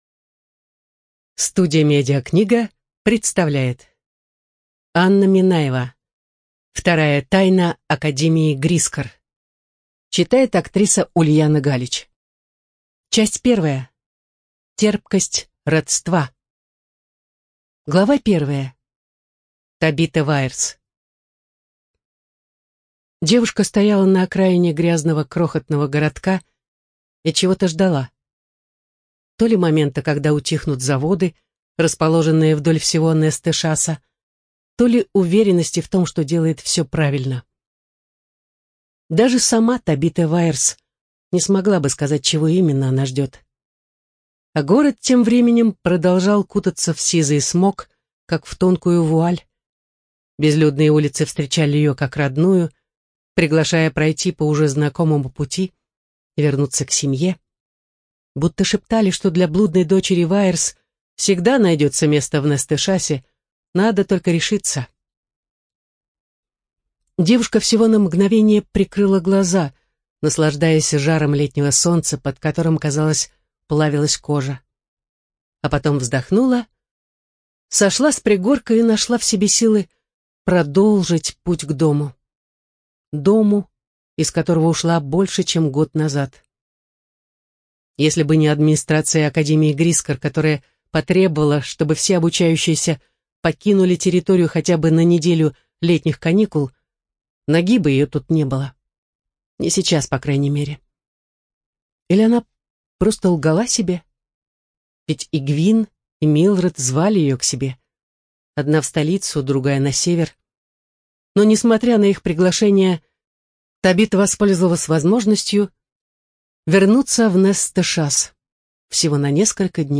Студия звукозаписиМедиакнига